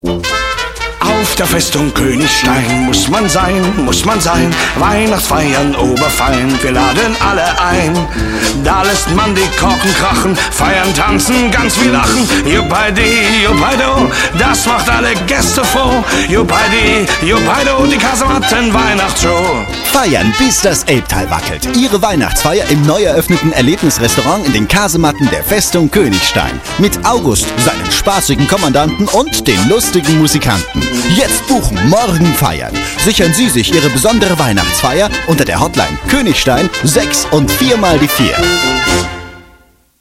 Werbespot